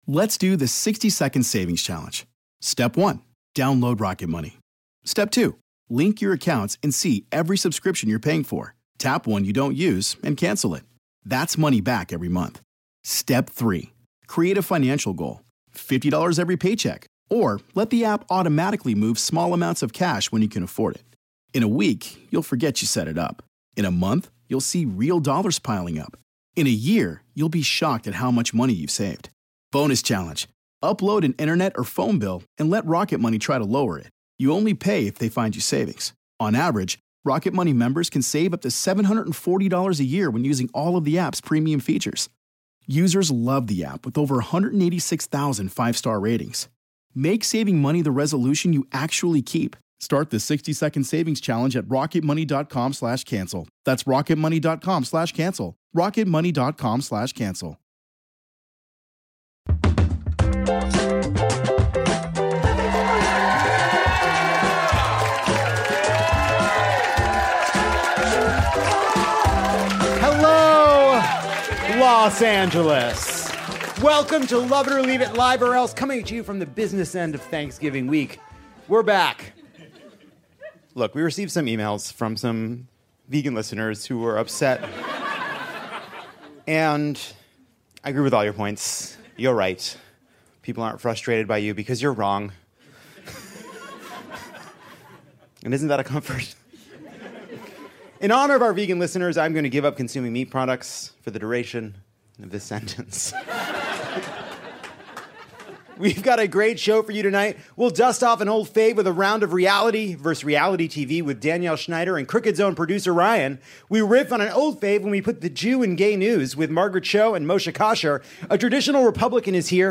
It’s a December to remember as Lovett or Leave It returns to Los Angeles’s beautiful Dynasty Typewriter and we celebrate Christmas with a new slate of “traditional” holiday rom-coms. Margaret Cho and Moshe Kasher gather 'round for a very special Hanukah-inspired edition of Gay News: Gay Jews.